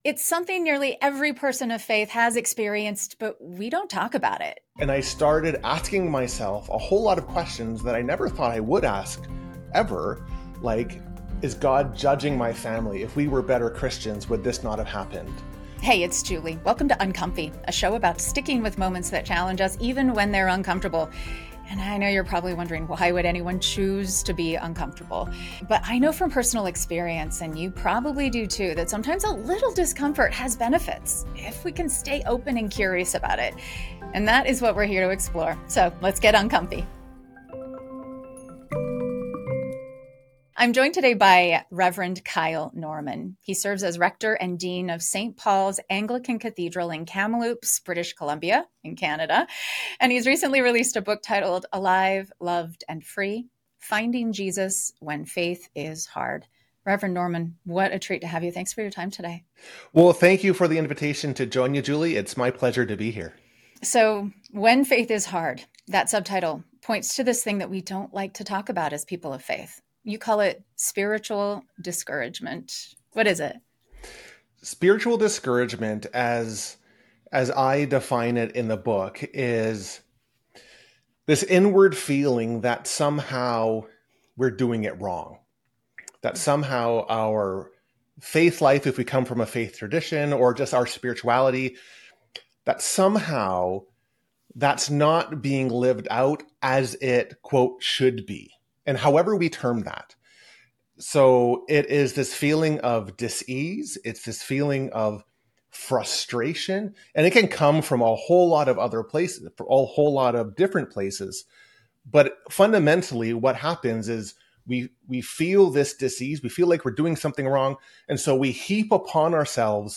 “Is God Even Listening?” An Honest Easter Conversation